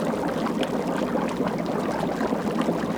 BUBBLE CAU01.wav